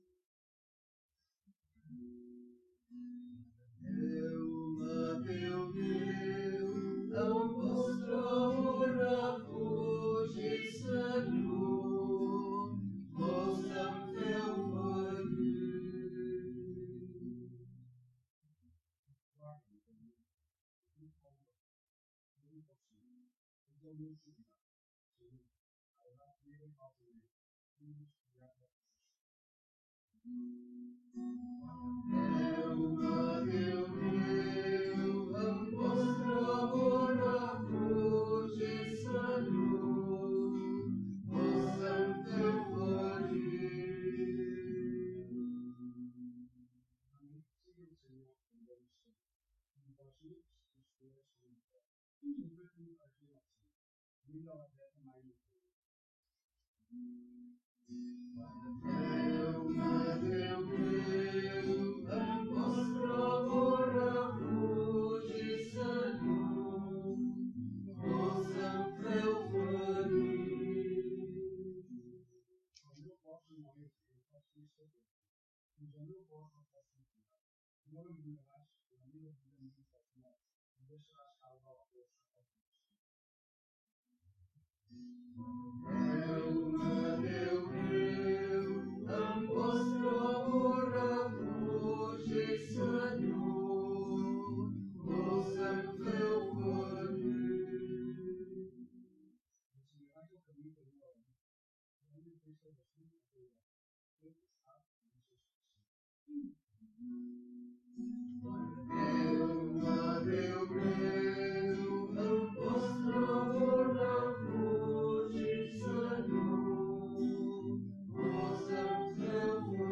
Pregària de Taizé a Mataró... des de febrer de 2001
Capella dels Salesians - Diumenge 30 d'abril de 2017